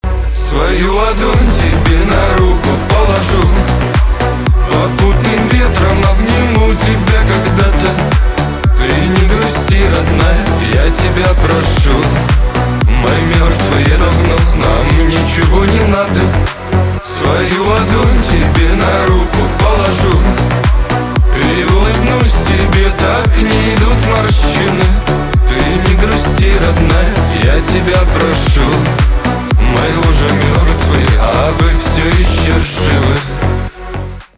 шансон
битовые , басы , грустные
чувственные